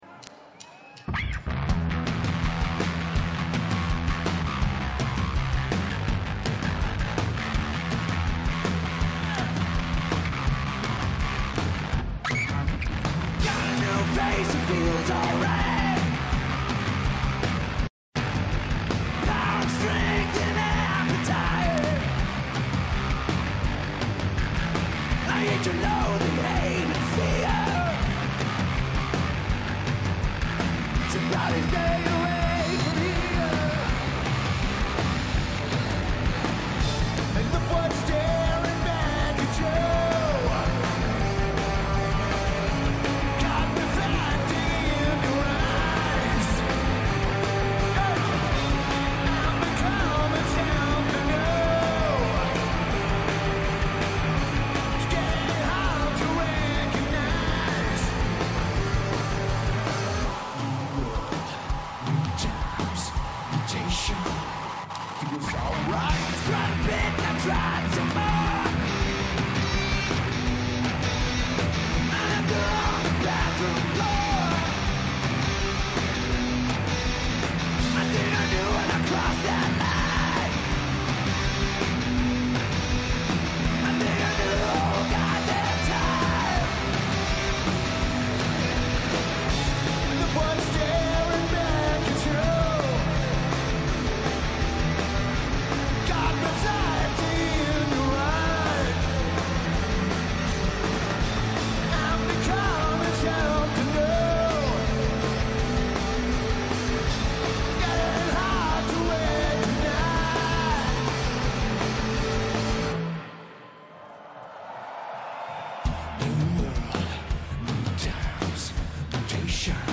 Mad Cool Festival
Madrid Spain
Lineage: Audio - SBD (Live Stream Audio)
Notes: Soundboard audio ripped from the live stream.